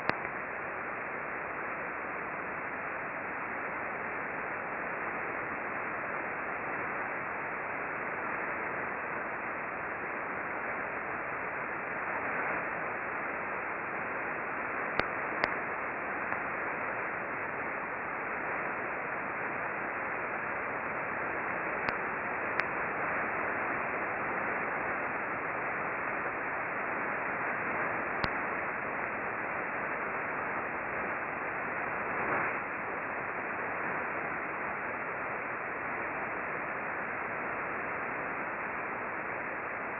The ambient RF noise level in this direction is fairly high (over 300k K).
We observed mostly S-bursts that shifted from receiver to receiver during the bursting periods.